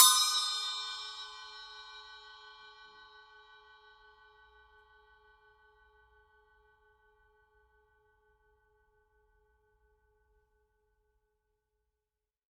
7.5" Cup Chime
Weight: medium, Volume: soft to medium loud, Stick Sound: dry, pingy, Intensity: lively
Sound: bright, warm, full, brilliant. Wide range, clean mix. Soft, extremely responsive. Explosive, shattering, glassy attack with modulating warm, full, long fade. Features an exotic, very dominant center tone.
Sustain: long
Bell Character: full, loud
7.5_cup_chime_edge.mp3